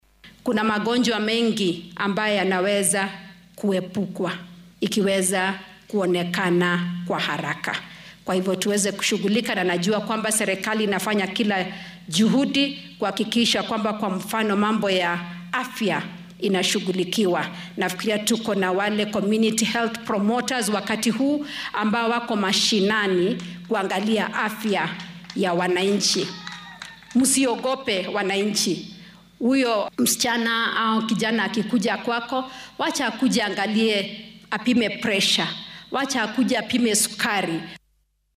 Marwada koowaad ee dalka Rachel Ruto ayaa bulshada ugu baaqday in ay soo dhaweyso oo howlaha ay qabanayaan loo fududeeyo shakhsiyaadka si iskood ah uga shaqeeya caafimaadka bulshada ee CHVs. Xilli ay munaasabad aas ahayd uga qayb gashay degmada Nangili ee ismaamulka Kakamega ayay dhanka kale dowladda ku bogaadisay taageerada ay howlwadeennadan u fidinaysa.